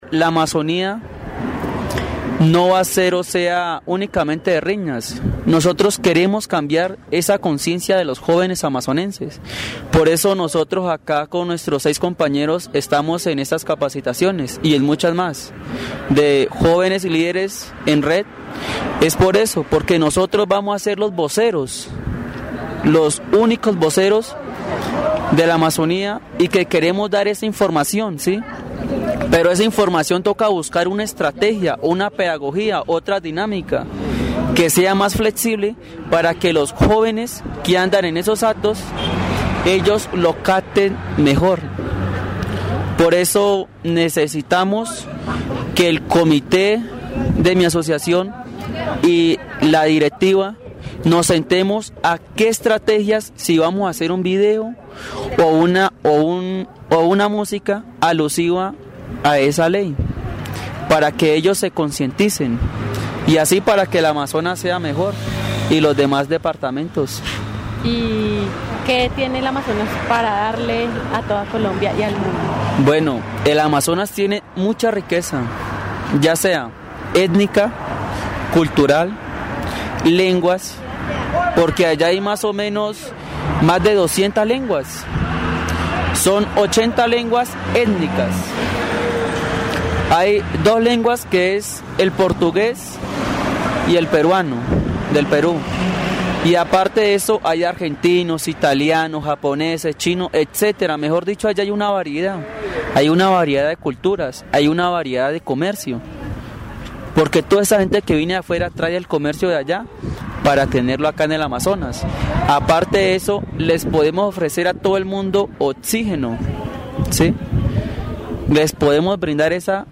El programa radial "Voces y Regiones" presenta una entrevista con un grupo de jóvenes líderes de la Amazonía, quienes trabajan en estrategias para concientizar a su comunidad sobre la importancia de preservar su entorno y cultura. Los entrevistados destacan la diversidad étnica y lingüística de la región, mencionando la existencia de más de 200 lenguas, entre ellas 80 lenguas étnicas, así como la presencia de comunidades extranjeras que han aportado al comercio local. Además, resaltan el valor de las plantas medicinales y la medicina tradicional como un recurso para el tratamiento de diversas enfermedades.